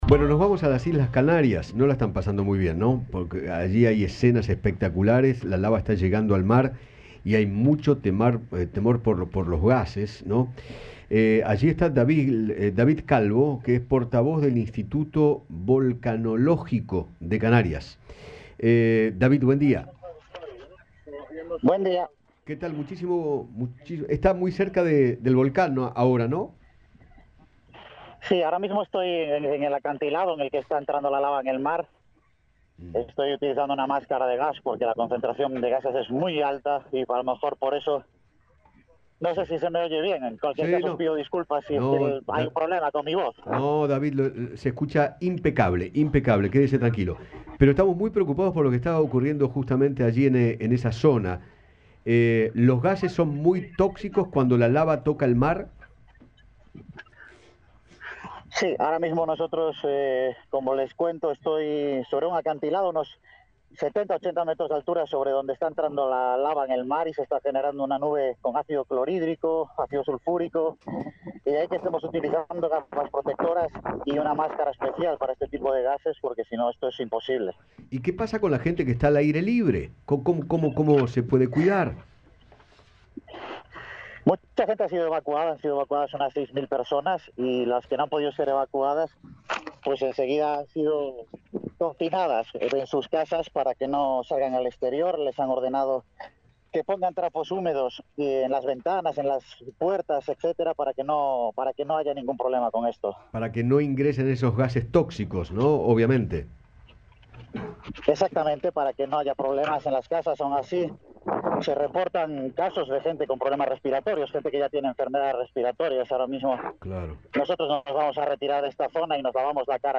dialogó con Eduardo Feinmann acerca de la llegada de la lava del volcán Cumbre Vieja al mar y explicó las consecuencias que podría generar.